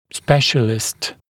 [‘speʃəlɪst][‘спэшэлист]специалист